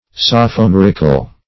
Meaning of sophomorical. sophomorical synonyms, pronunciation, spelling and more from Free Dictionary.